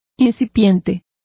Complete with pronunciation of the translation of incipient.